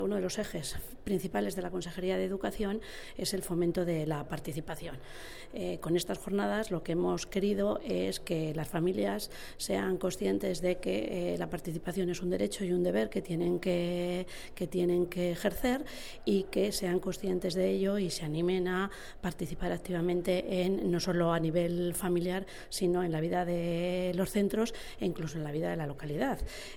La viceconsejera de Educación habla de la importancia de la participación de las familias en los centros educativos.